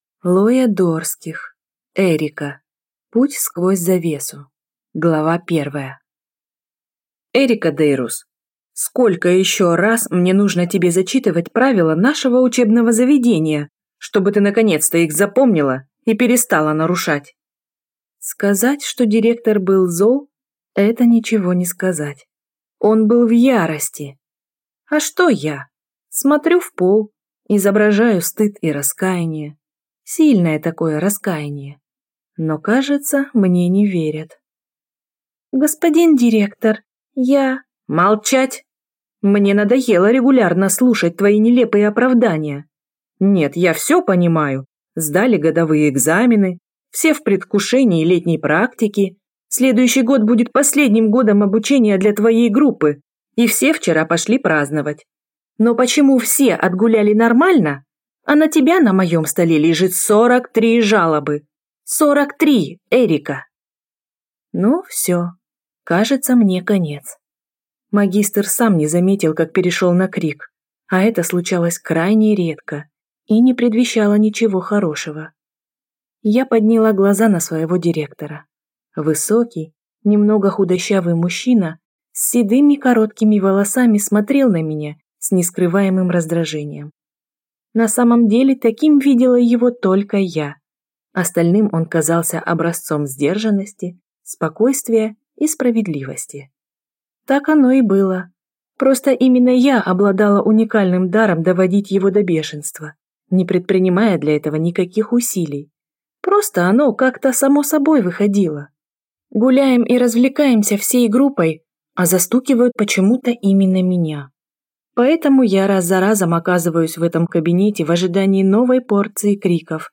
Аудиокнига Эрика. Путь сквозь завесу | Библиотека аудиокниг